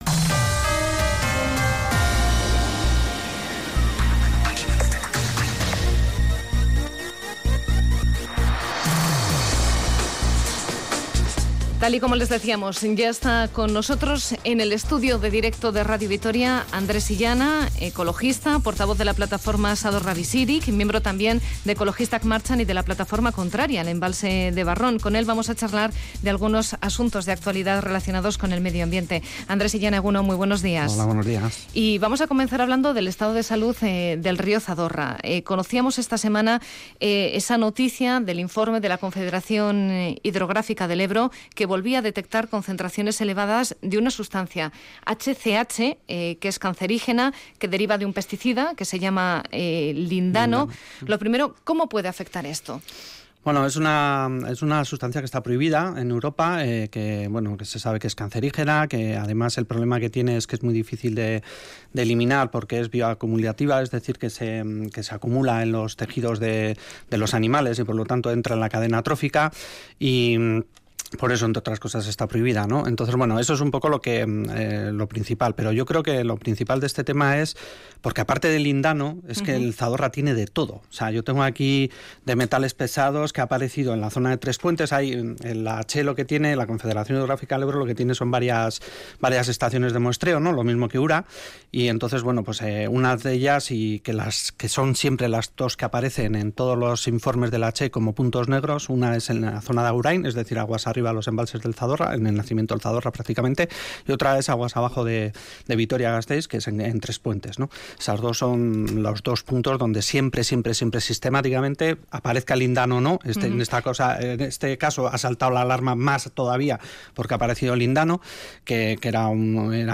ha hablado en Radio Vitoria sobre los vertidos en el río Zadorra, que se agravan